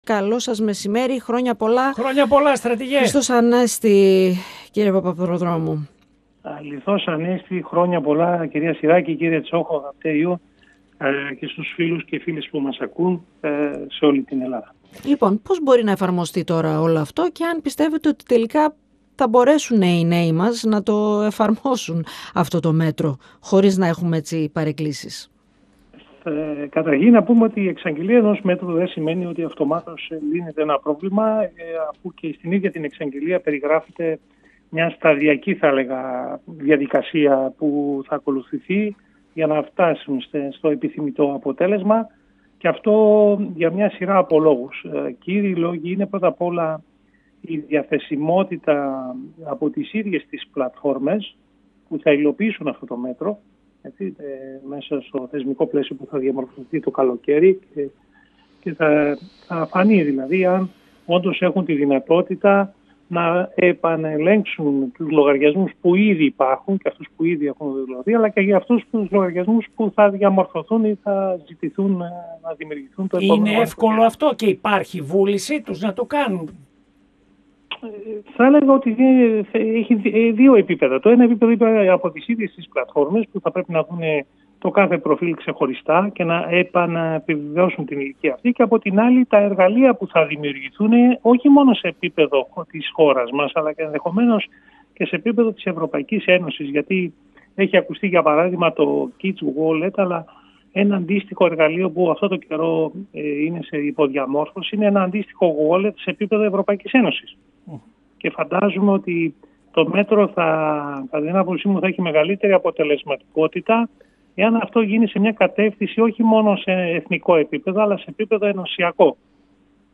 Για την απαγόρευση των social media σε άτομα κάτω των 15 ετών, μίλησε στον 102fm, ο πρώην Διευθυντής Δίωξης Ηλεκτρονικού Εγκλήματος, Γιώργος Παπαπροδρόμου.
Για την απαγόρευση των social media σε άτομα κάτω των 15 ετών, μίλησε στον 102fm, ο πρώην Διευθυντής Δίωξης Ηλεκτρονικού Εγκλήματος, Γιώργος Παπαπροδρόμου. 102FM Επομενη Σταση: Ενημερωση Συνεντεύξεις ΕΡΤ3